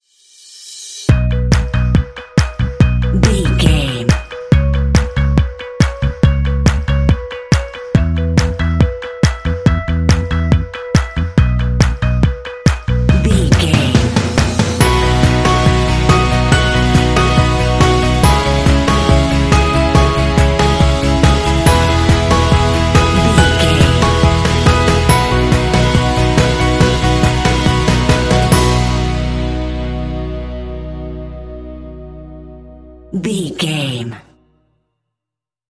Uplifting
Ionian/Major
driving
joyful
electric guitar
bass guitar
drums
percussion
piano
rock
pop
alternative rock
indie